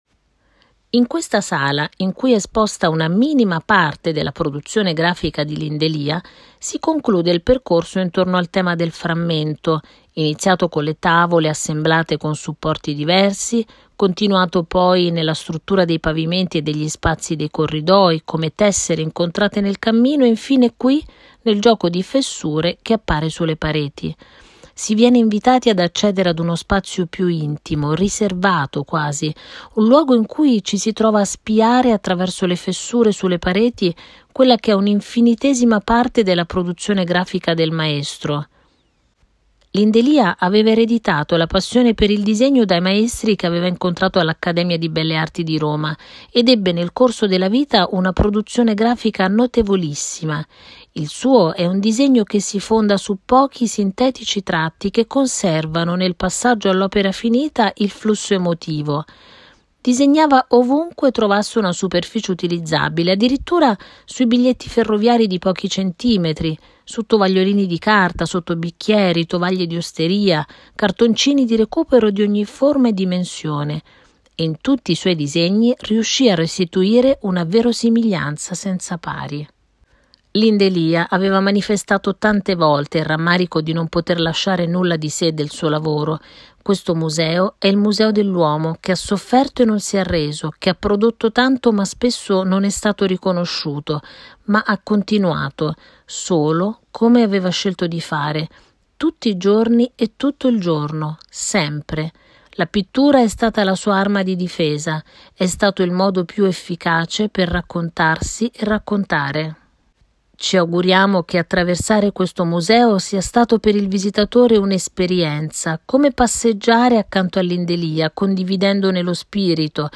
Testo Audioguide